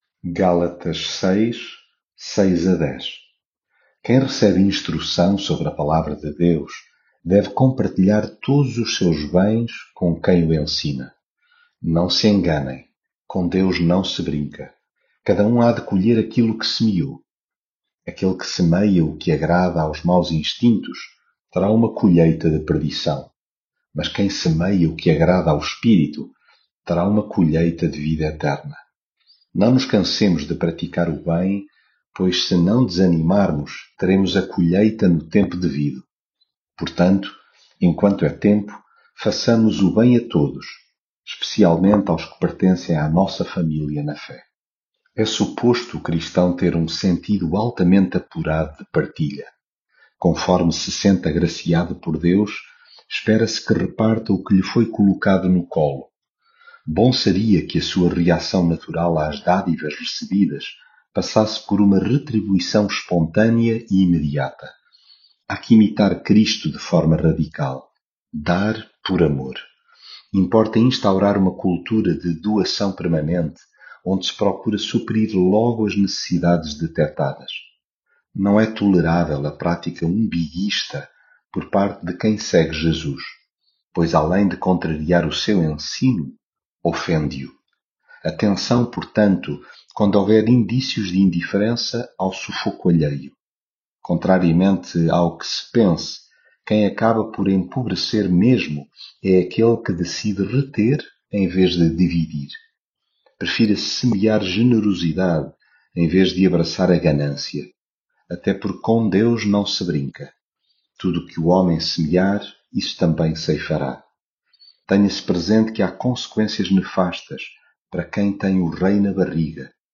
devocional gálatas
leitura bíblica